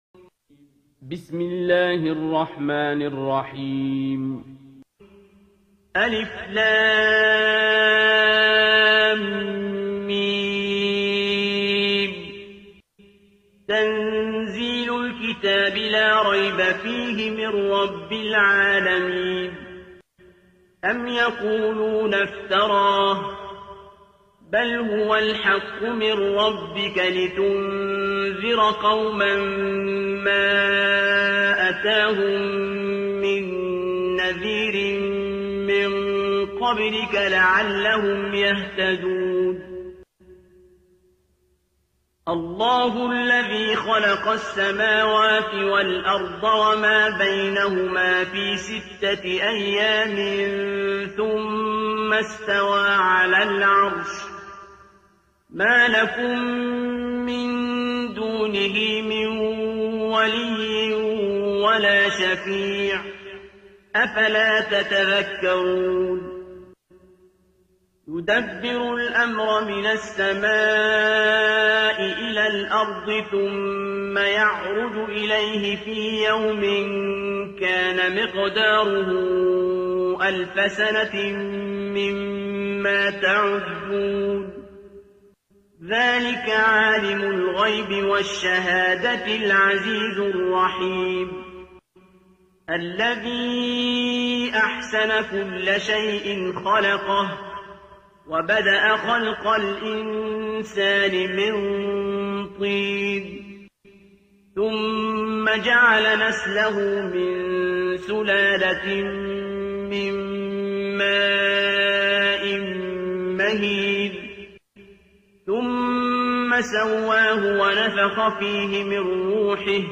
ترتیل سوره سجده با صدای عبدالباسط عبدالصمد
032-Abdul-Basit-Surah-As-Sajda.mp3